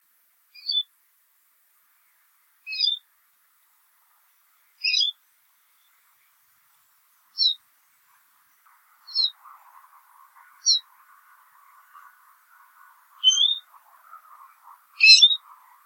El so és un metàl·lic
“tsil·lú”, “sl·luí”.